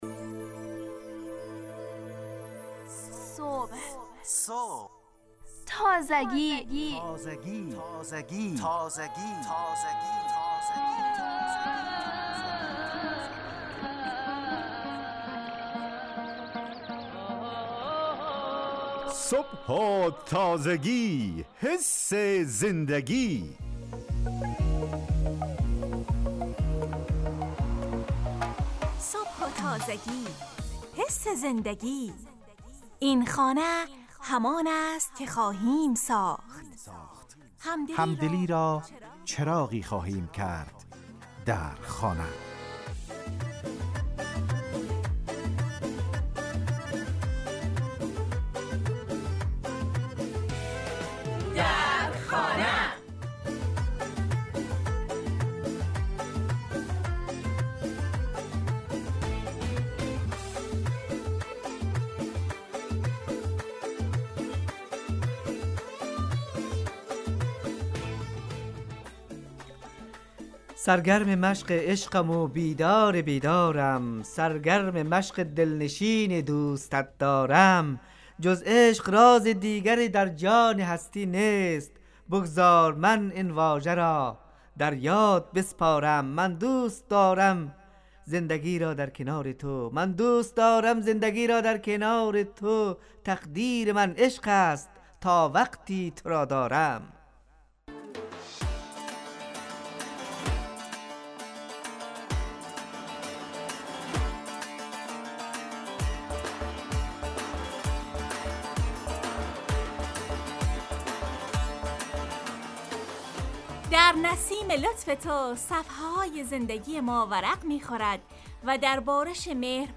در این برنامه موضوع جمع بندی شده و نیز گفتگویی با خانم دلبر نظری وزیر امور زنان افغانستان شده.